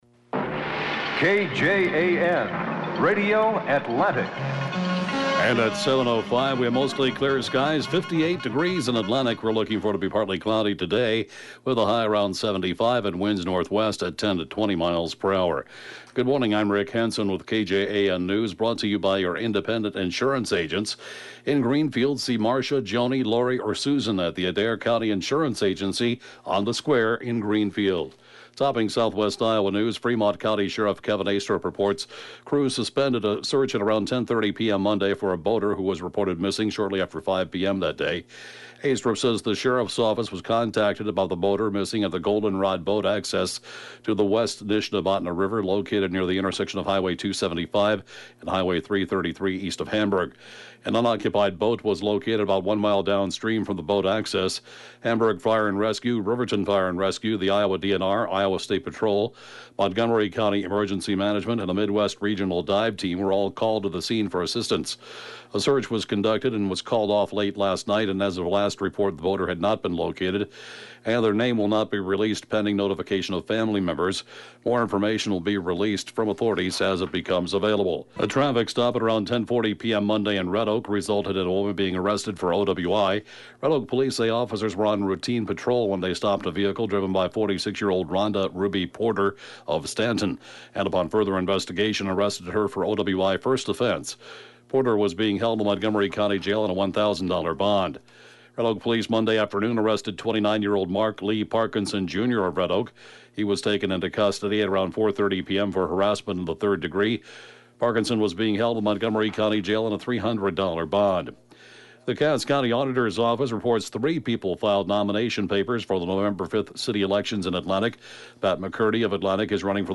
(Podcast) KJAN Morning News & Funeral report, 8/27/19